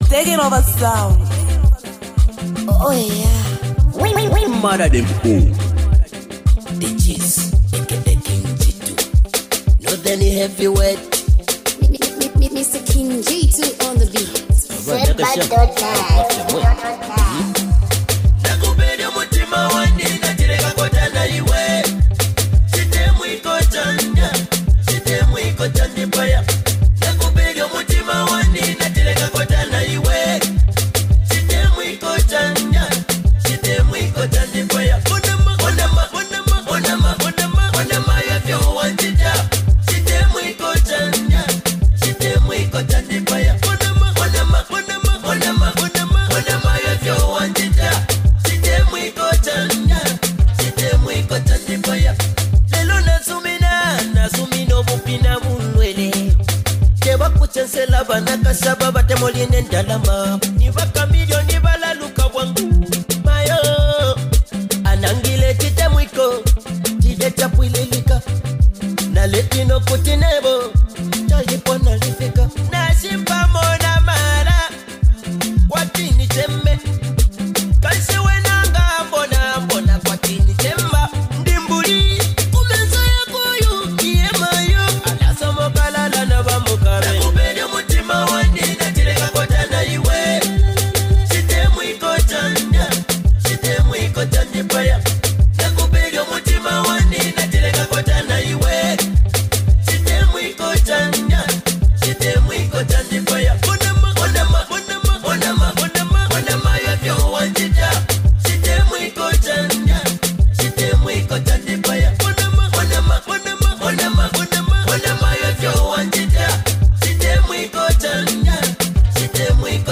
Category: Zambian Music